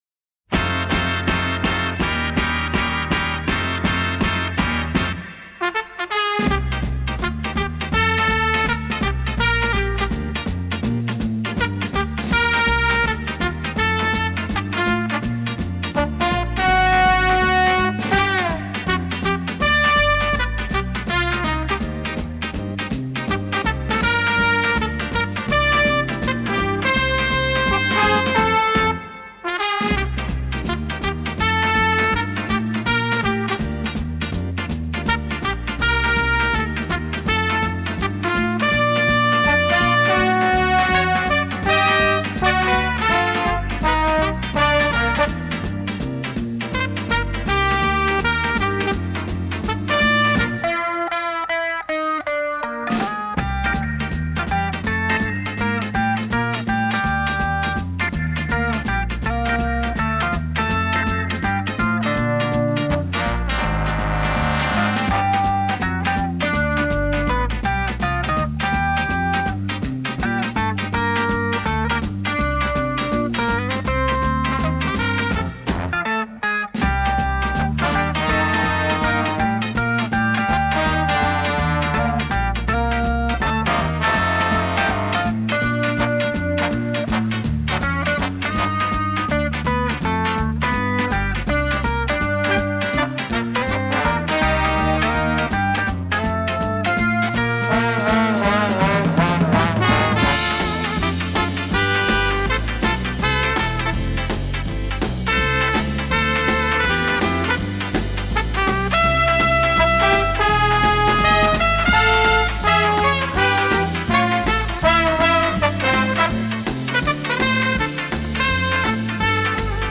Джаз
популярная идиома двухбитового джаза - простая,